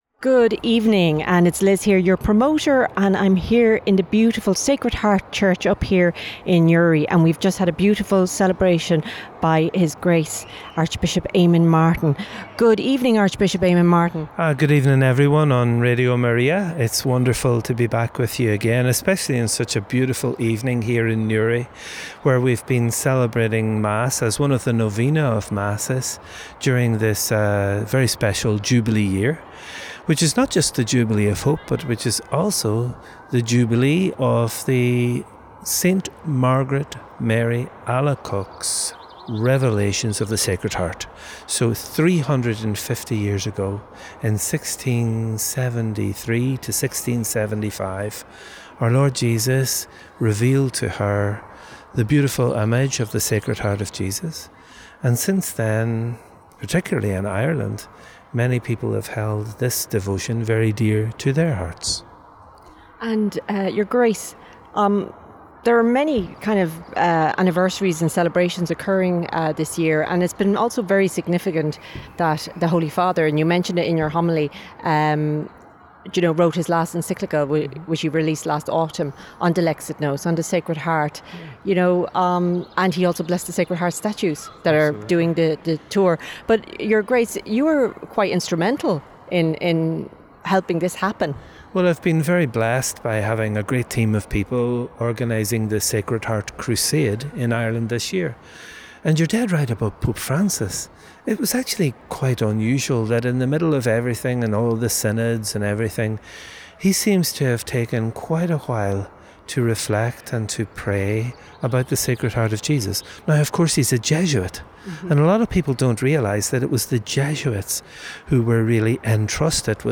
Interview with Archbishop Eamonn Martin at the Sacred Heart Novena Mass in Newry – Sacred Heart Crusade
Archbishop Eamonn Martin was interviewed at the celebration of the Sacred Heart Novena Mass in Newry on Friday evening, as part of the preparation for the 22nd of June Sacred Heart consecration at Knock, and the Sacred Heart Feast on 27th June
Archbishop-Eamonn-Martin-Interview.mp3